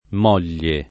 [ m 0 l’l’e ]